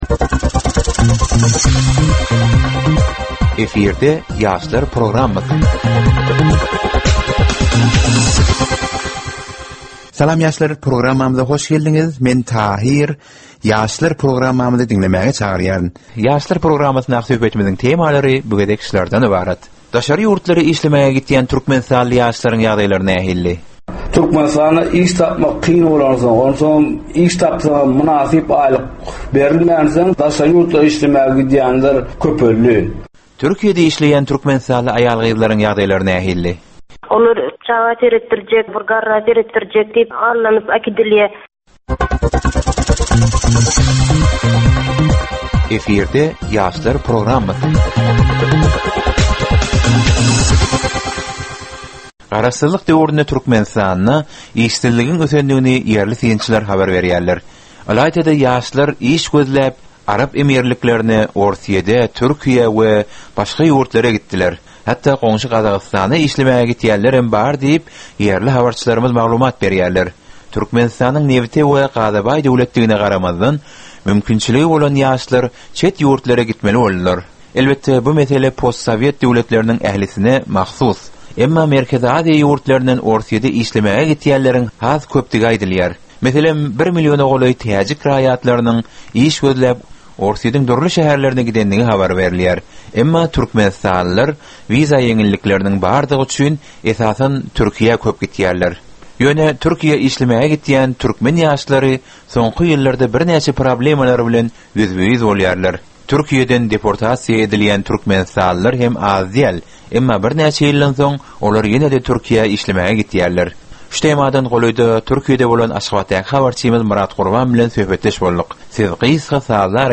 Türkmen we halkara yaşlarynyň durmuşyna degişli derwaýys meselelere we täzeliklere bagyşlanylyp taýýarlanylýan ýörite gepleşik. Bu gepleşikde ýaşlaryň durmuşyna degişli dürli täzelikler we derwaýys meseleler barada maglumatlar, synlar, bu meseleler boýunça adaty ýaşlaryň, synçylaryň we bilermenleriň pikirleri, teklipleri we diskussiýalary berilýär. Gepleşigiň dowamynda aýdym-sazlar hem eşitdirilýär.